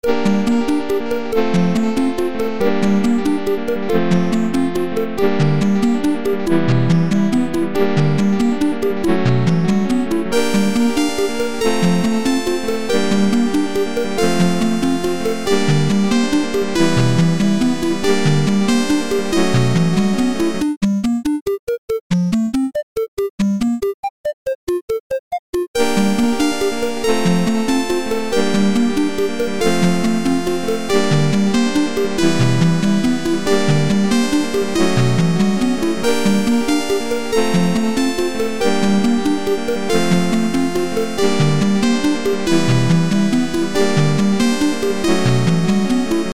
Peach is a little different than triforce because it has a tone bank rather than just the square and triangle, but it's still trying to be NES sounding. This is a more straight-forward tonal thing with cheep arpeggiated chords.
Filed under: Instrumental